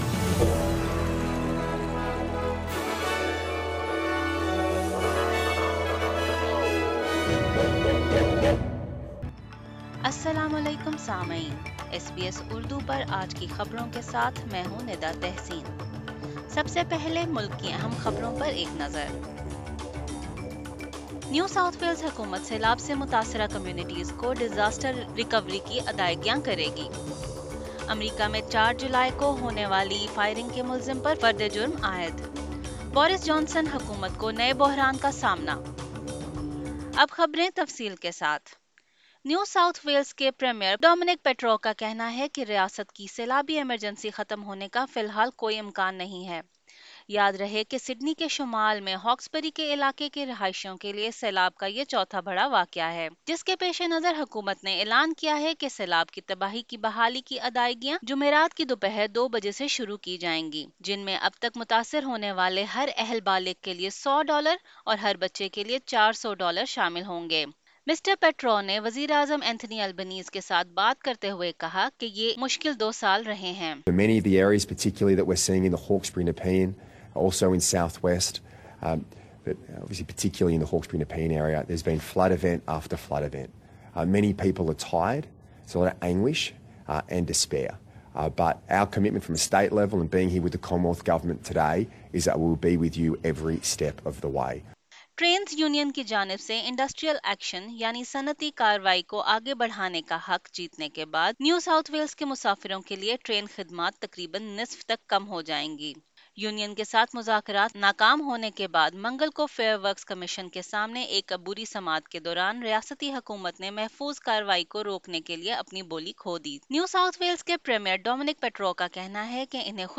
SBS Urdu News 06 July 2022